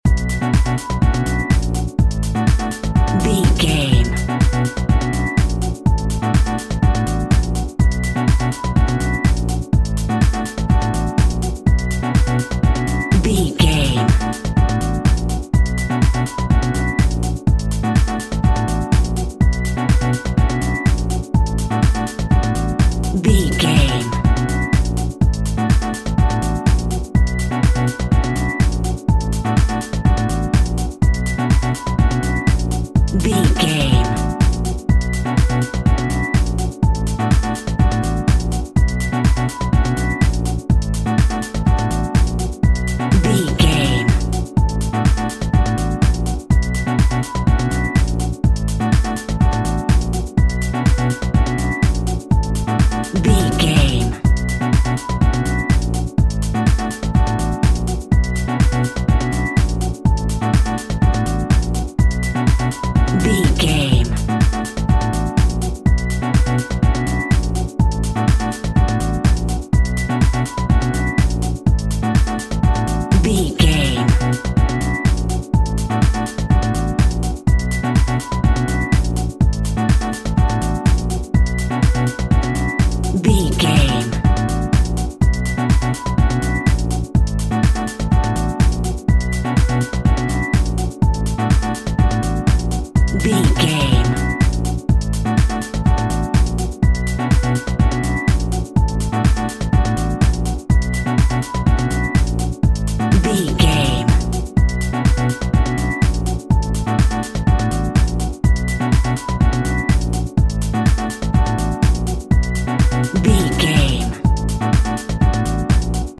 Aeolian/Minor
funky
groovy
uplifting
energetic
electric organ
electric piano
synthesiser
drum machine
Lounge
nu jazz
downtempo
on hold music
synth bass